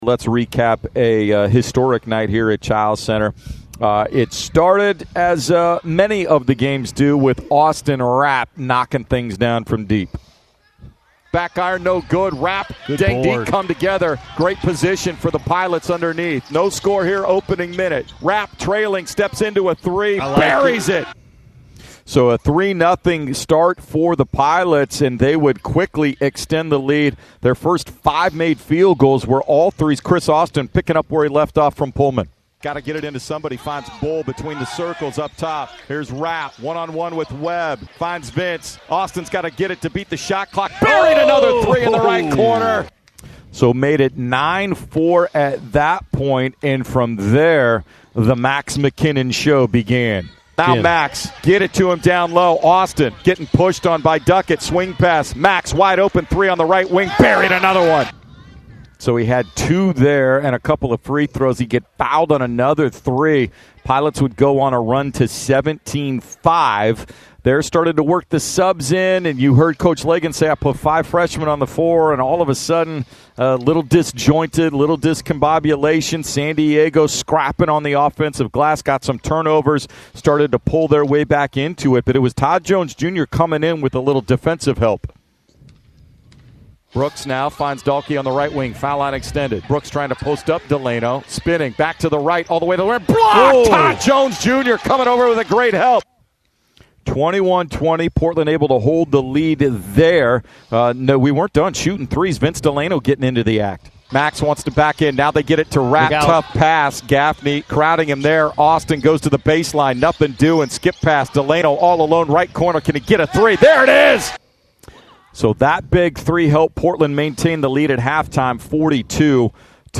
Radio Highlights vs. San Diego